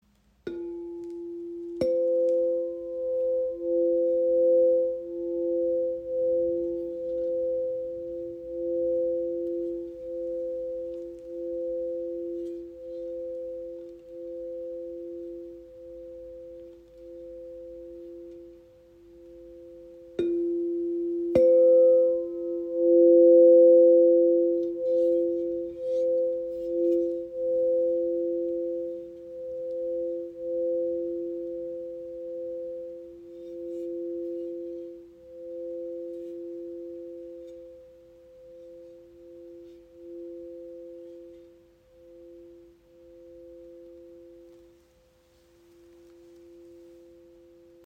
Die Wave F/C in 432 Hz ist ein handgefertigtes Klanginstrument in der heiligen Quinte das Harmonie, Ruhe und Energiefluss schenkt.
Der Klang breitet sich weich und klar im Raum aus, ohne zu dominieren, und lädt Dich ein, langsamer zu werden, tiefer zu atmen und wieder bei Dir selbst anzukommen.